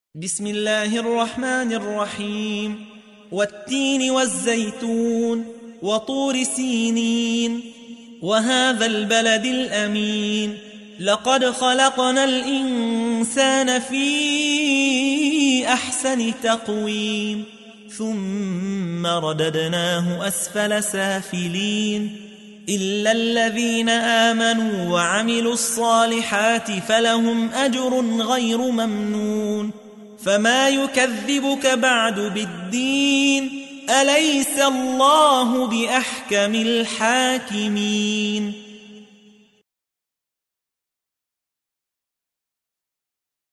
تحميل : 95. سورة التين / القارئ يحيى حوا / القرآن الكريم / موقع يا حسين